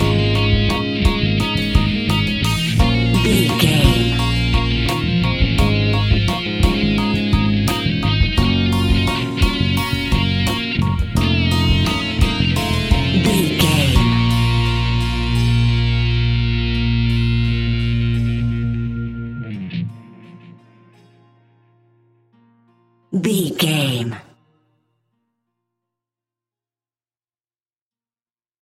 In-crescendo
Thriller
Aeolian/Minor
scary
ominous
suspense
eerie
Horror Pads
Horror Synths
Horror Ambience